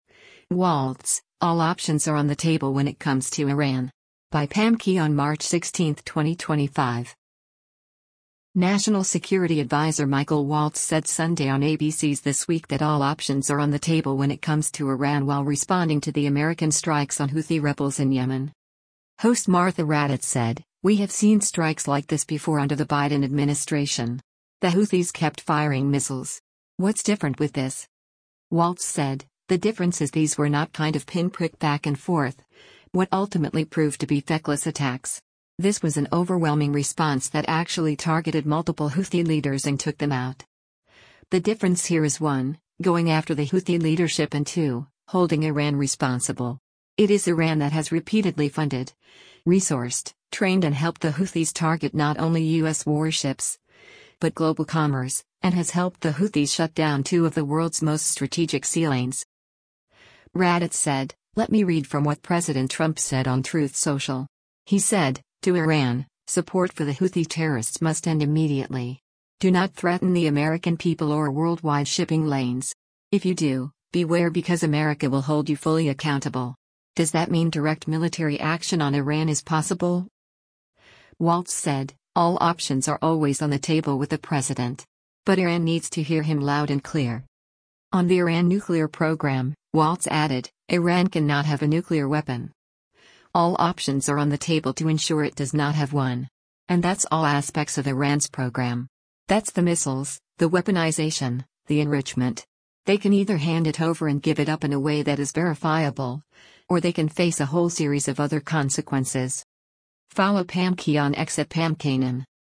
National Security Advisor Michael Waltz said Sunday on ABC’s “This Week” that “all options are on the table” when it comes to Iran while responding to the American strikes on Houthi rebels in Yemen.